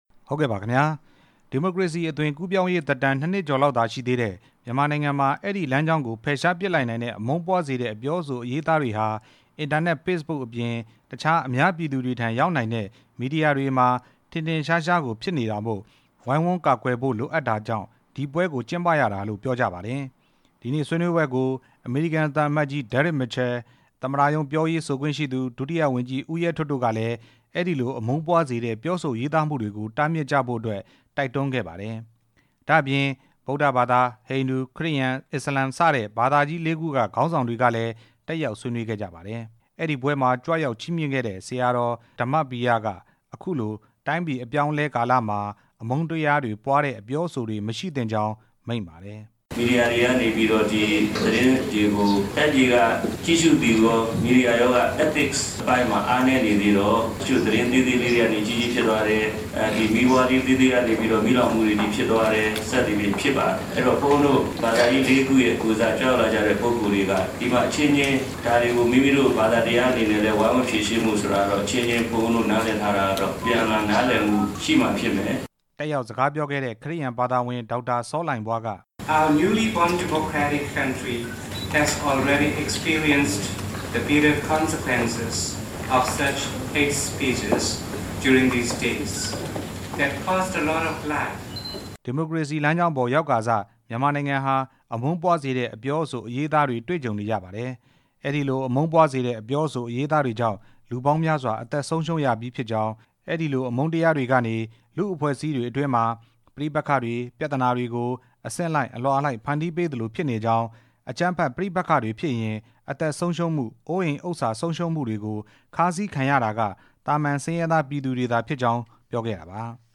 အခမ်းအနားအကြောင်း တင်ပြချက်